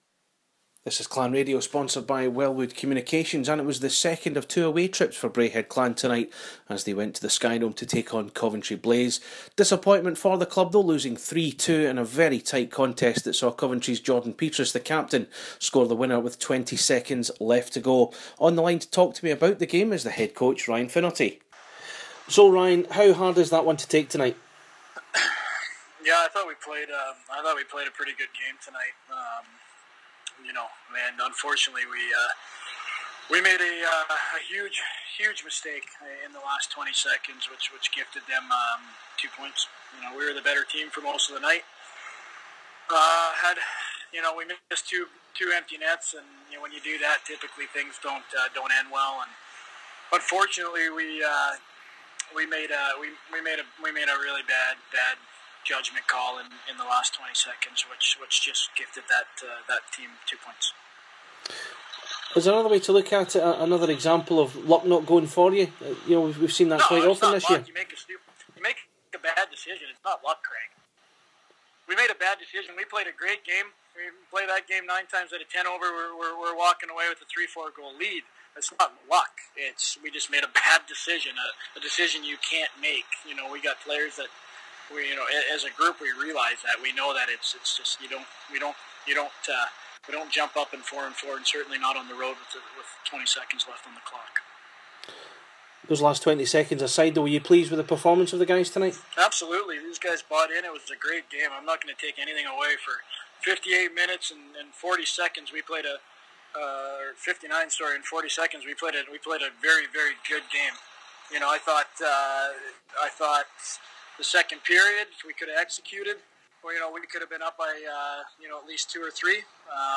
Post match reflections